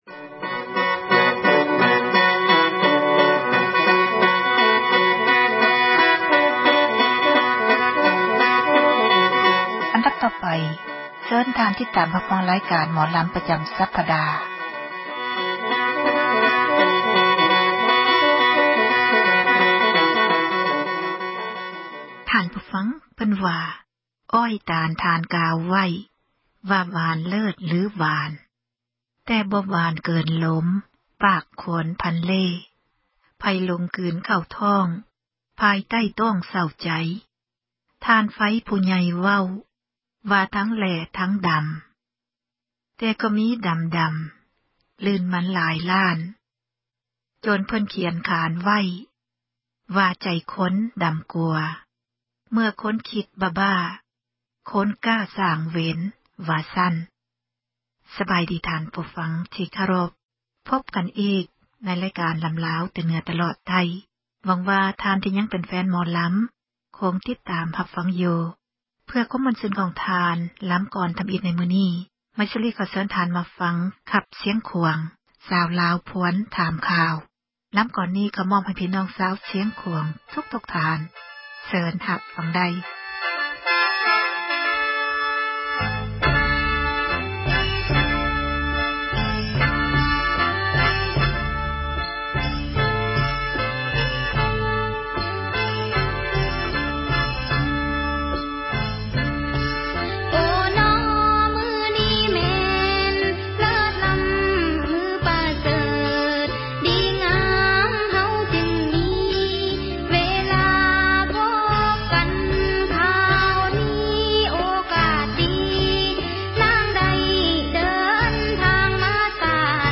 ຣາຍການ ໝໍລຳລາວ ປະຈຳ ສັປດາ ສເນີໂດຍ